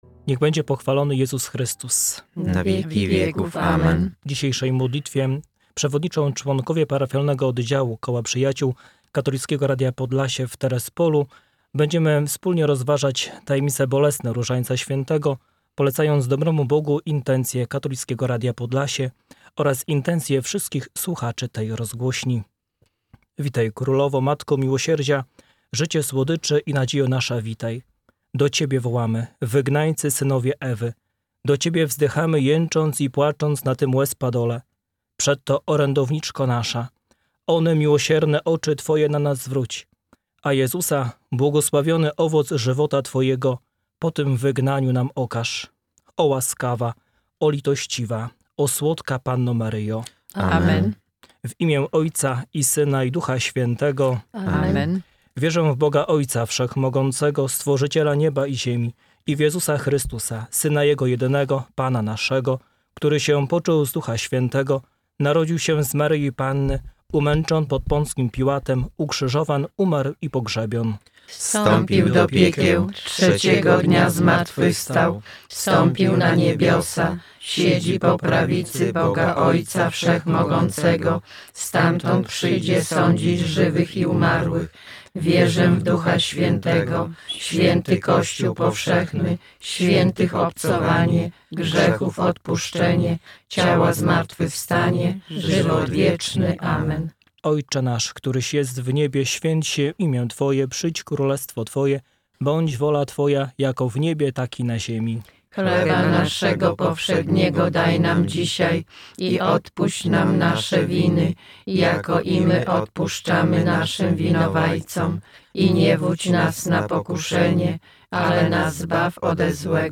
Przedstawiciele Koła Przyjaciół KRP na antenie Katolickiego Radia Podlasie, dnia 15 kwietnia 2014